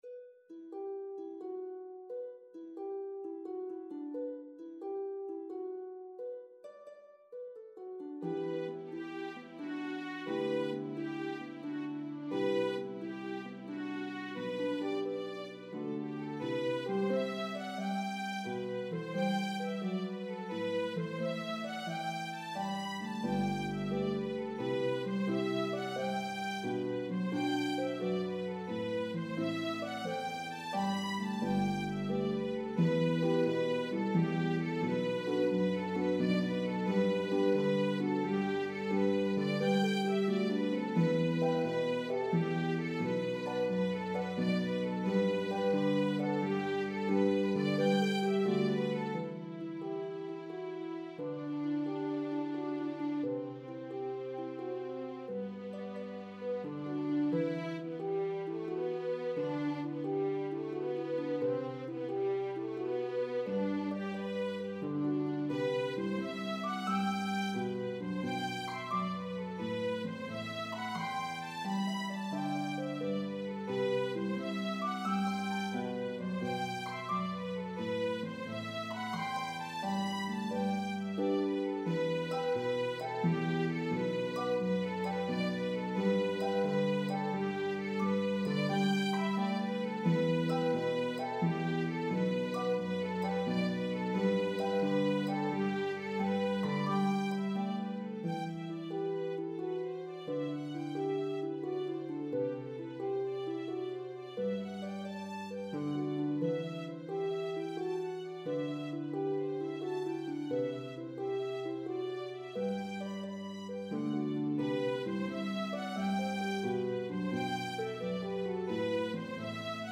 This entertaining 5+ minute medley begins sweetly
slip jig in 9/8
reel in 4/4
jig in 6/8
The melodies are shared between instruments.